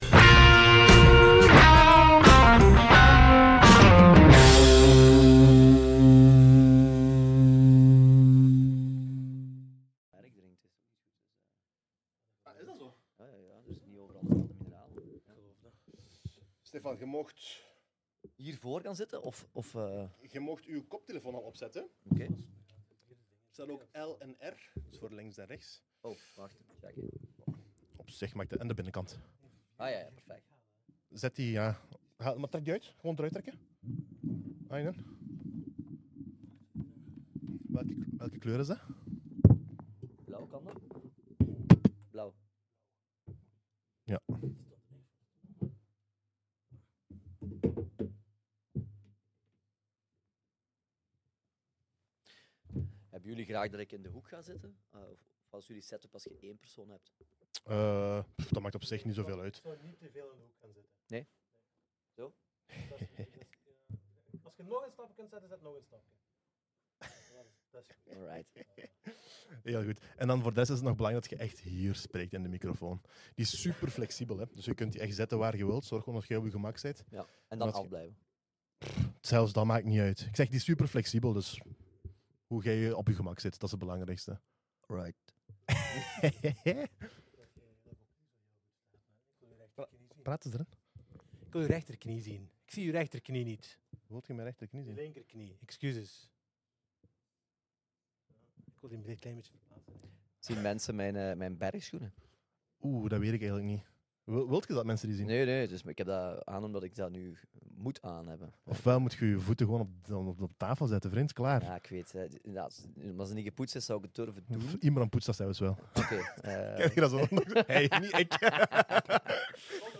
Luister hier naar het perspectief van politici, entertainers, ondernemers, atleten en meer!